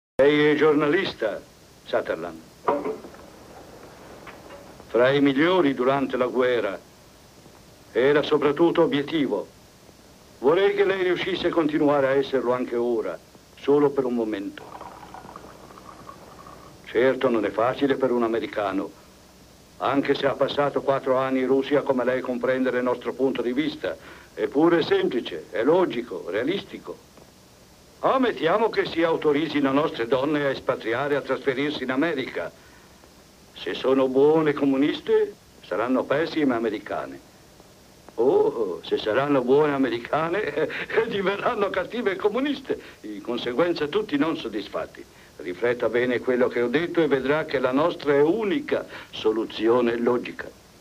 nel film "Non lasciarmi", in cui doppia Frederick Valk.